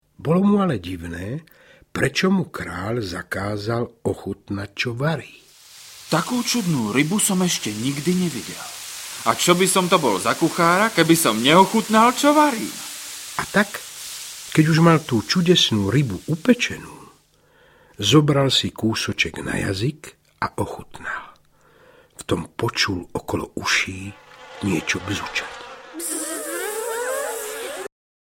Obsahuje rozprávky Zlatovláska a O kocúrovi, kohútovi a kose, v podaní výborného Mariána Labudu.
Ukázka z knihy